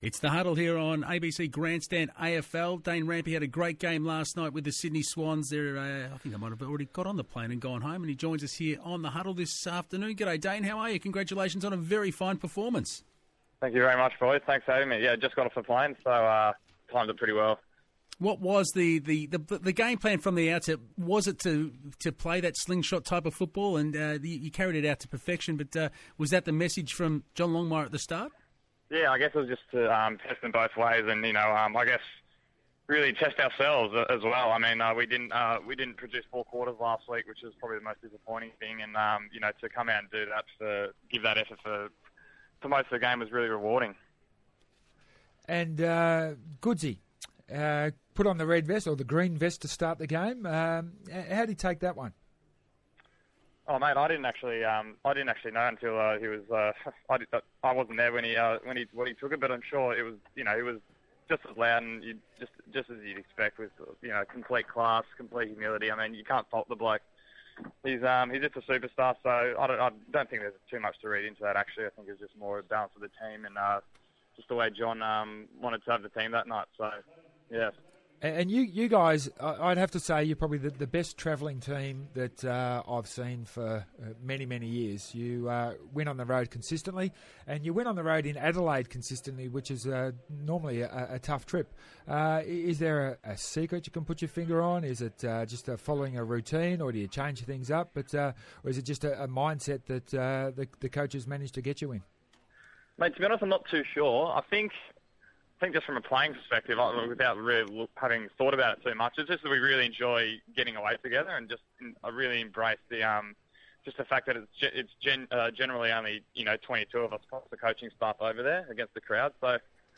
Dane Rampe speaks to ABC Radio after the club's round two win over Port Adelaide at the Adelaide Oval.